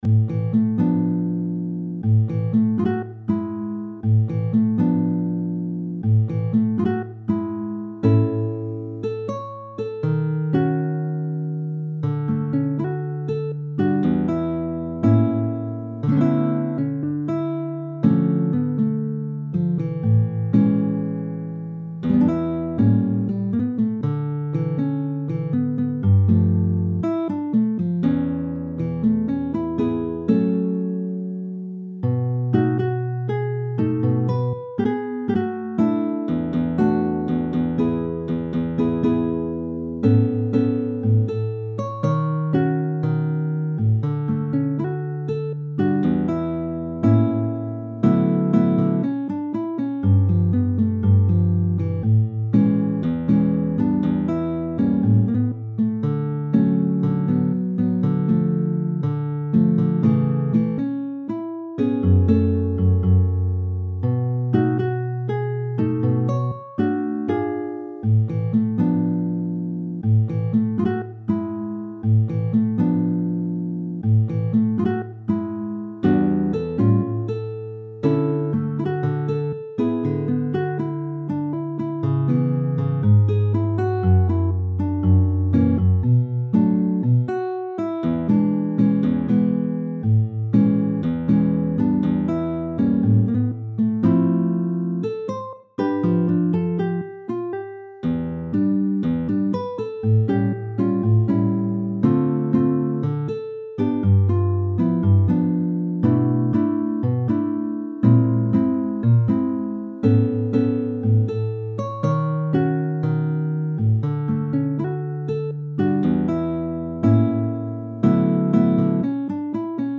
in la maggiore